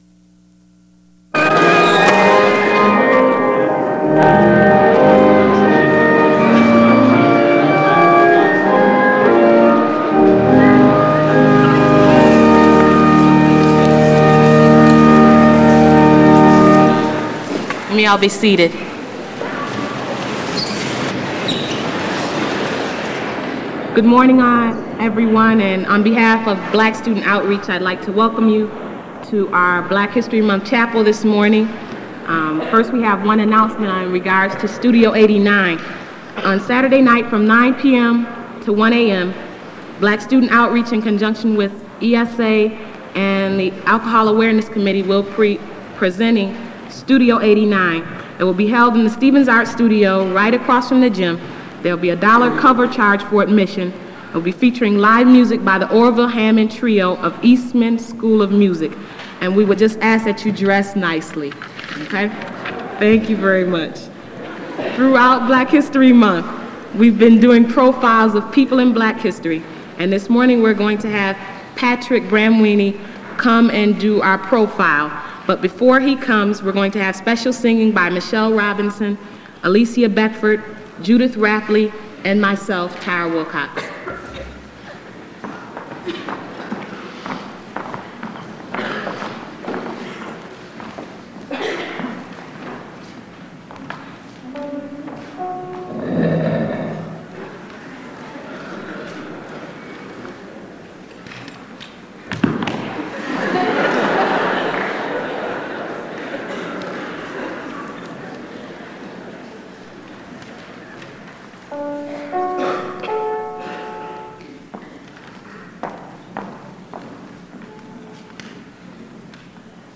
Black History Month chapel